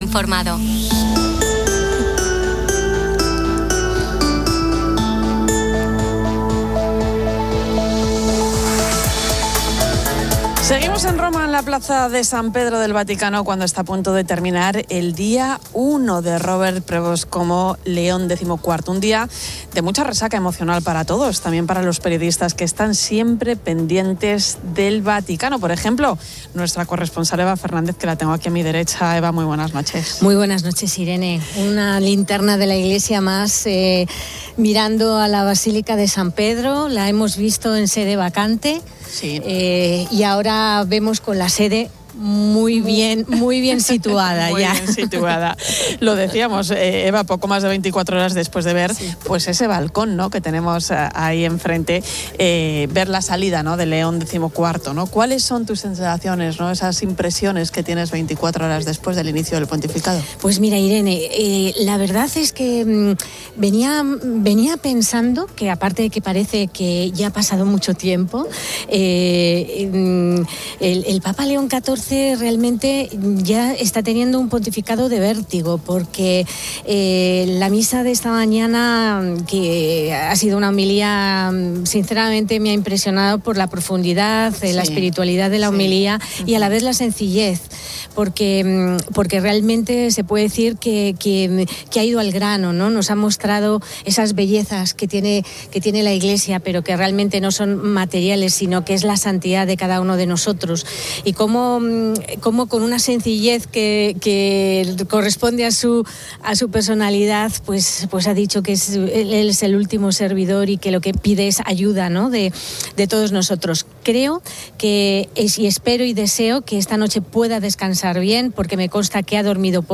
Seguimos en Roma en la Plaza de San Pedro del Vaticano cuando está a punto de terminar el día uno de Robert Prevost como León decimocuarto, un día de...